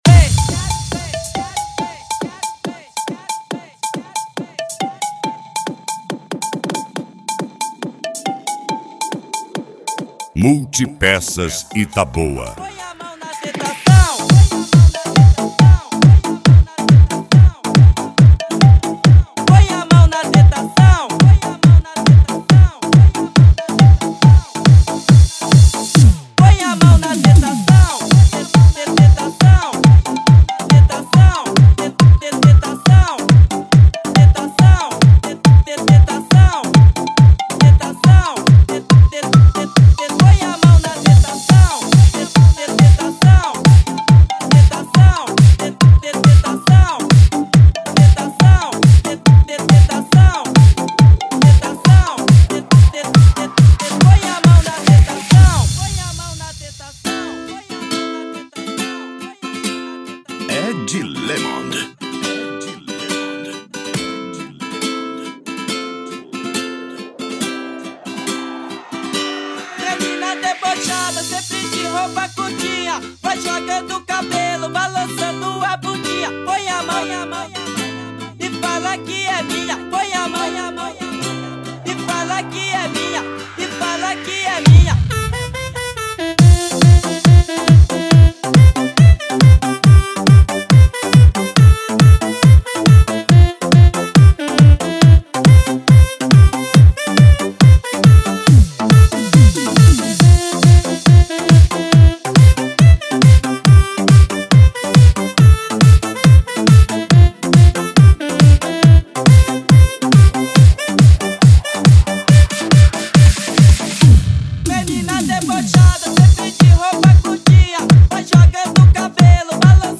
PANCADÃO